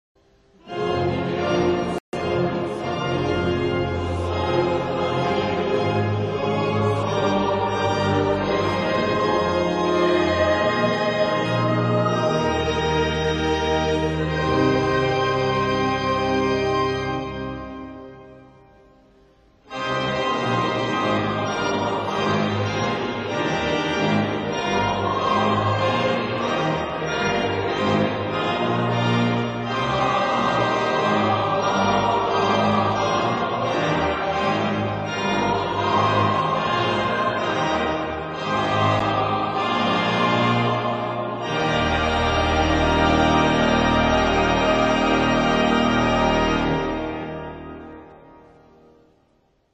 Sanctus, Agnus Dei, chœur à 4 voix mixtes et orgue (1968).